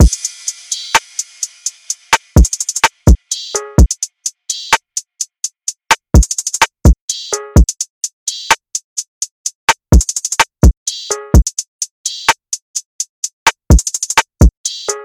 Sifu (127 BPM – Abm)
UNISON_DRUMLOOP_Sifu-127-BPM-Abm.mp3